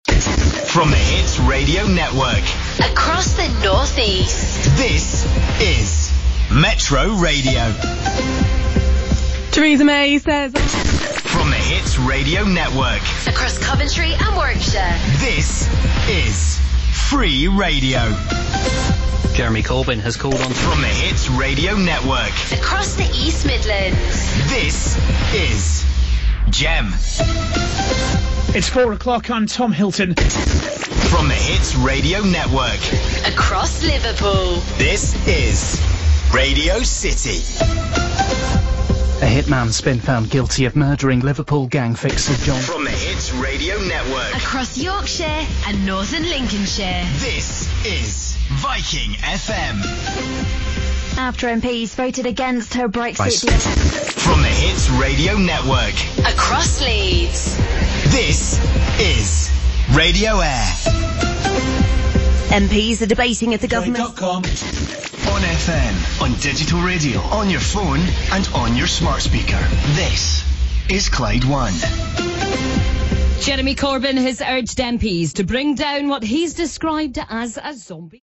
Facebook Twitter Headliner Embed Embed Code See more options Free Radio, Gem, Metro, Rock, Radio City etc are all now carrying branding identifying them as part of the Hits Radio Network. Check out this montage of station news intros in England from Wed 16th Jan 2019. For reference, we've included the same intro for Clyde 1, which isn't using the Hits Radio Network wording.